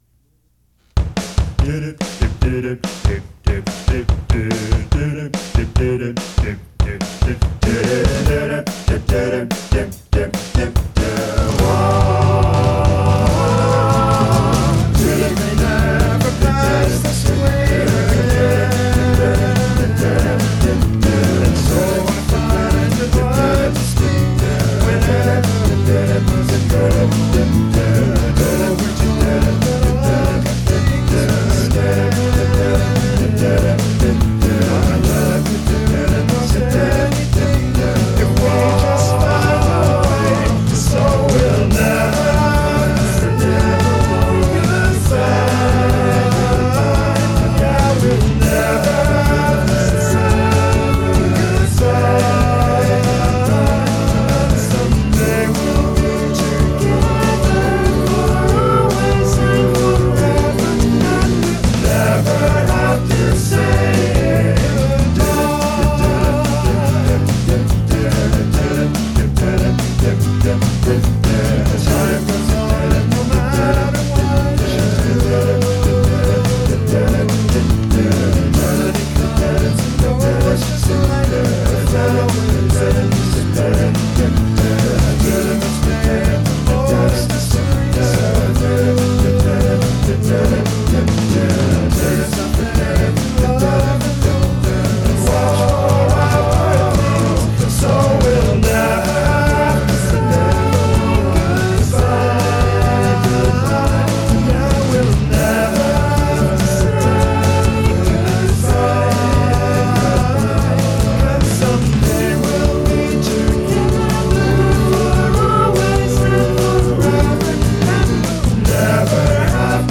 vocals / guitars / sequencing